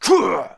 attack_3.wav